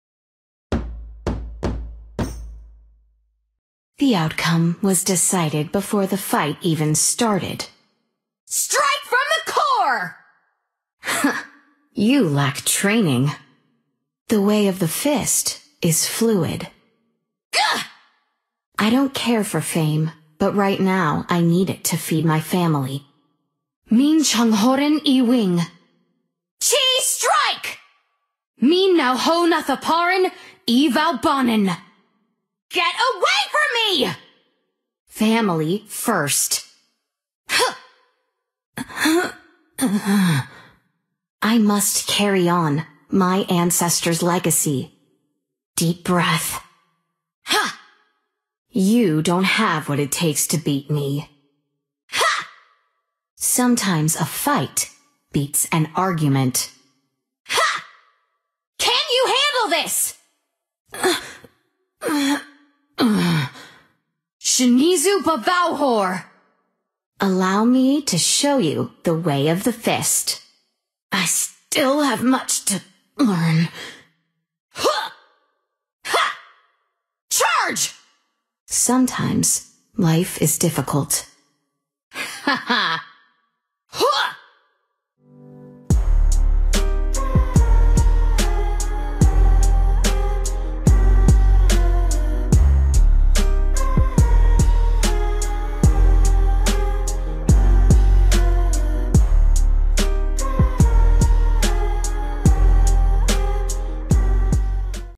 Bên dưới là toàn bộ voice line (voice over) của Qi và bản dịch giọng nói Tiếng Việt của Qi bánh bao.